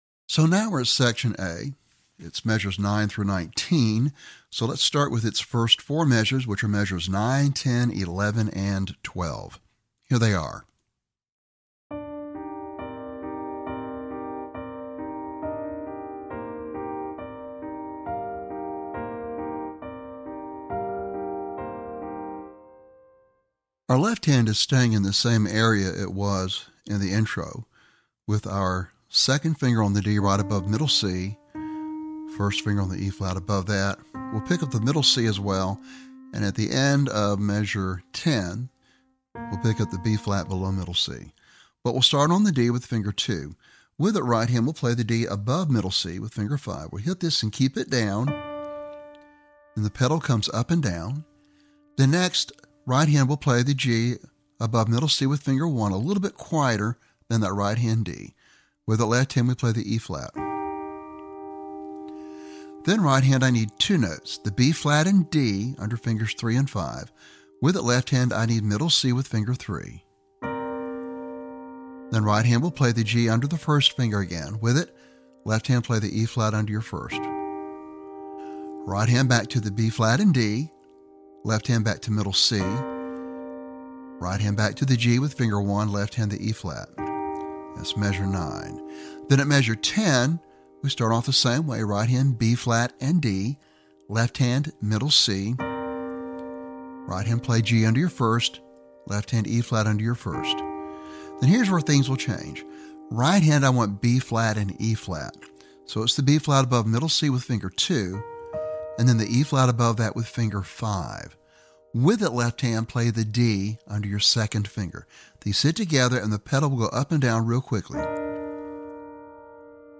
Lesson Sample
piano solo